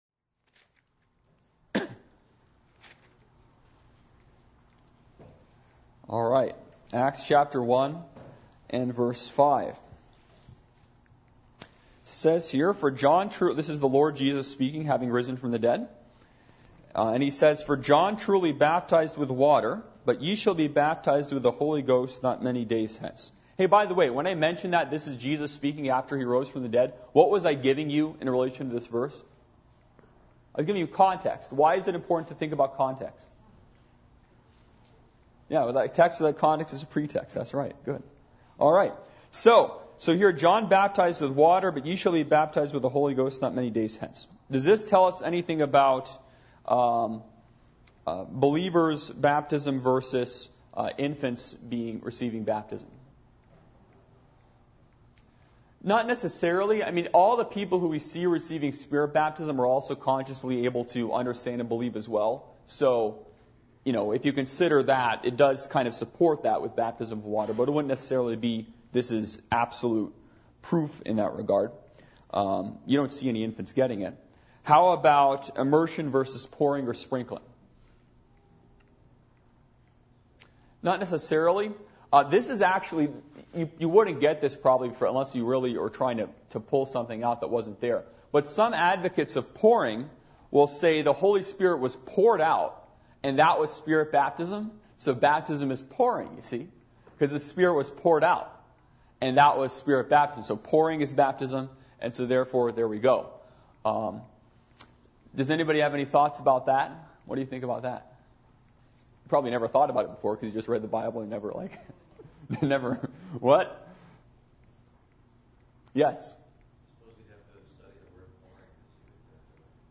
Service Type: Adult Sunday School